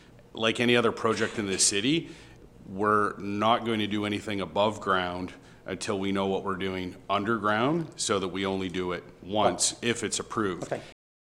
The City of Belleville’s Planning Advisory Committee hosted a public meeting Monday on the major development planned for the Black Bear Ridge Golf Course property.
Chair of the Planning Advisory Committee and Ward 2 Councillor Paul Carr noted that they will not make any approvals until they are sure about the infrastructure required underground.